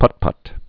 (pŭtpŭt) Informal